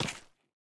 resin_step3.ogg